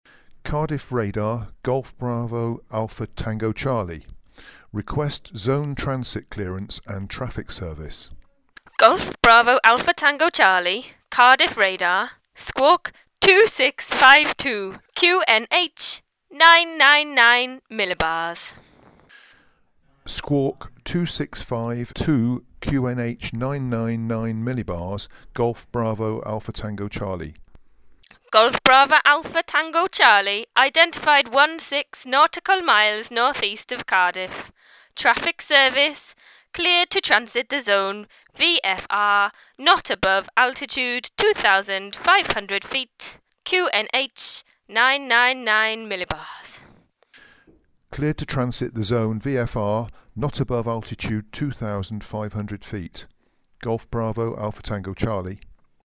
You’re going to listen to the calls a GA pilot would make on route from Swansea to Compton Abbas.
Listen to the relevant exchanges between pilot and ground (links are in the text).